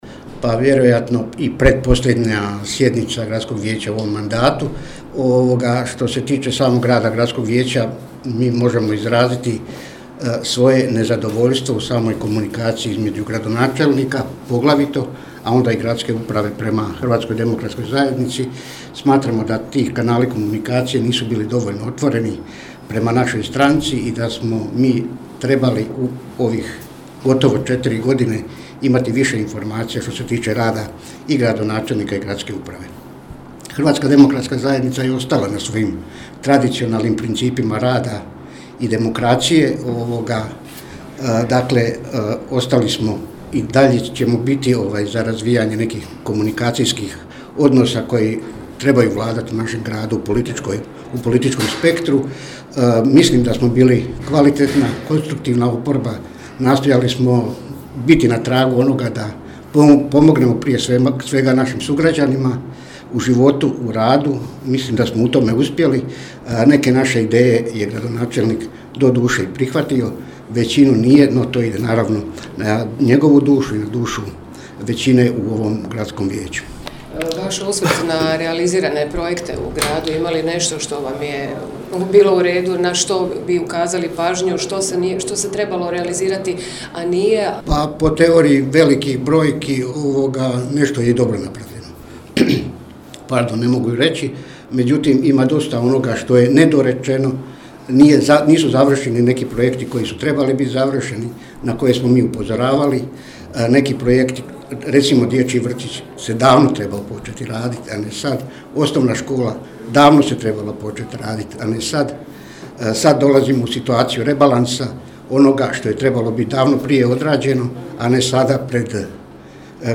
Neposredno prije današnje, 25. sjednice Gradskog vijeća Grada Nova Gradiška na kojoj se trebalo raspravljati o novom gradskom Proračunu, svoj stav o toj temi i funkcioniranju Gradskog vijeća u protekloj godini na tiskovnoj su konferenciji iznijeli vijećnici iz redova Hrvatske demokratske zajednice.
O radu Gradskog vijeća očitovao se i vijećnik HDZ-a Davorin Slišurić: